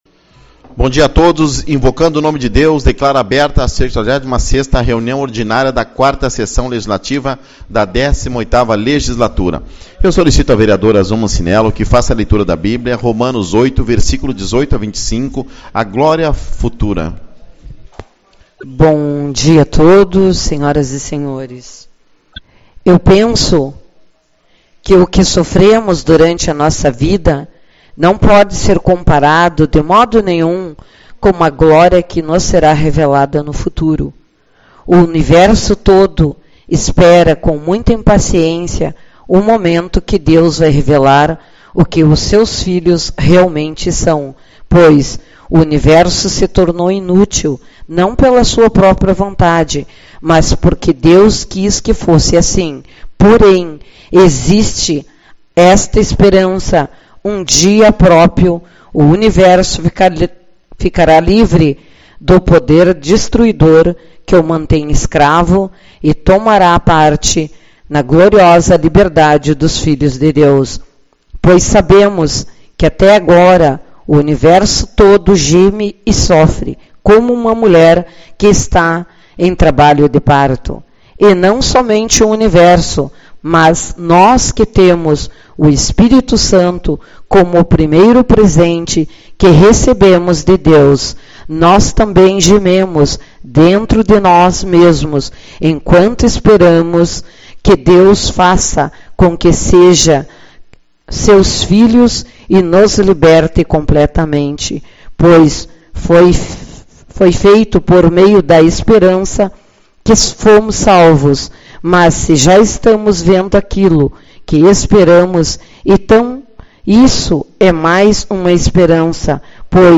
15/10 - Reunião Ordinária